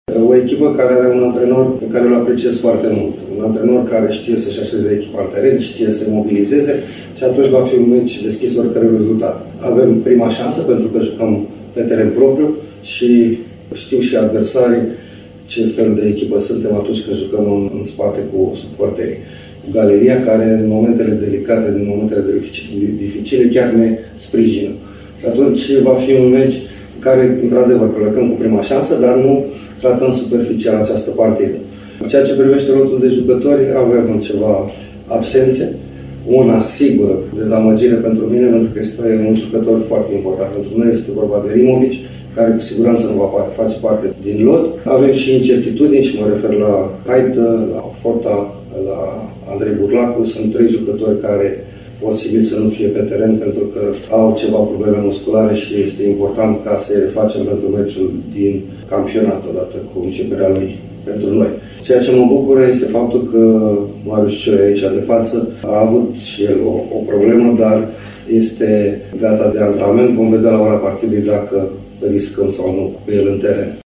Confruntarea de mâine a fost prefaţată de antrenorul cărăşenilor, Flavius Stoican, care a vorbit, printre altele, despre omologul său de pe banca albaiulienilor, dar şi despre indisponibilităţile pe care le are echipa sa, înaintea meciului care va hotărî echipa care va avea avantajul terenului propriu în sferturile de finală ale Cupei: